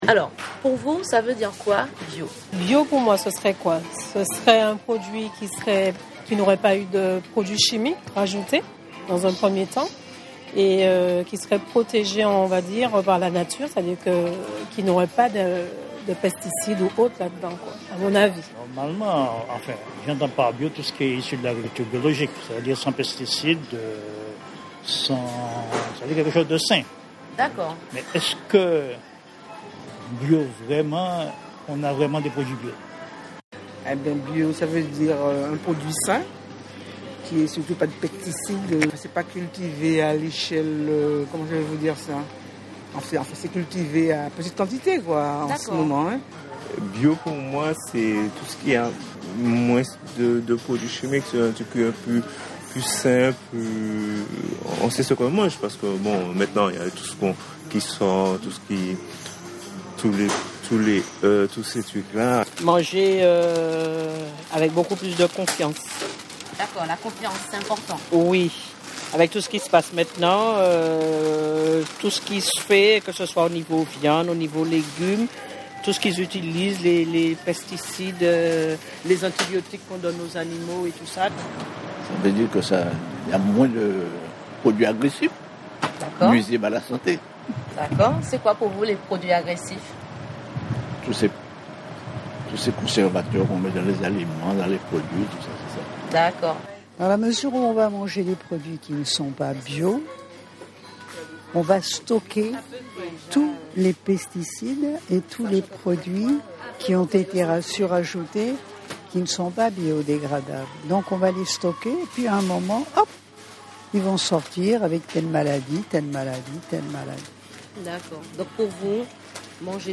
Mille merci à celles et ceux qui se prêtés au jeu du questions-réponses 😉 .